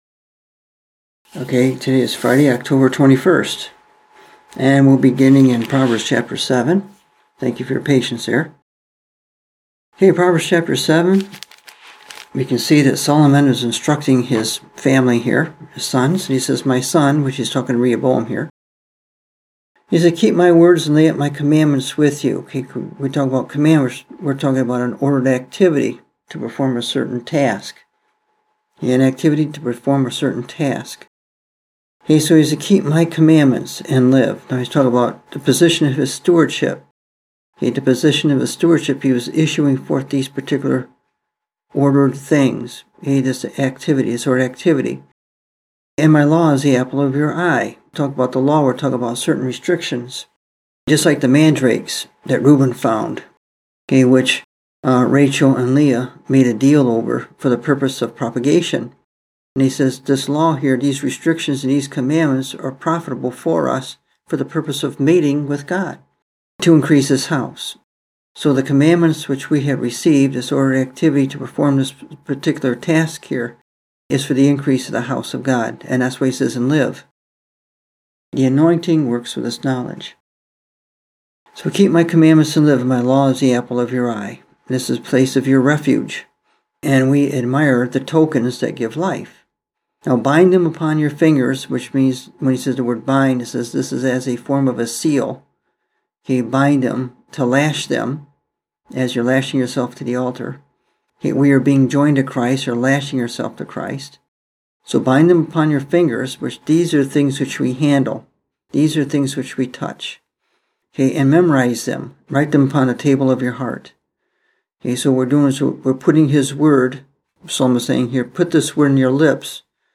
Listen to the message I preached October 21, 2015: God Makes the Distinction Between Substitutionsim & Propitiation